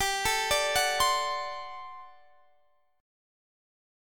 Listen to GM7sus2sus4 strummed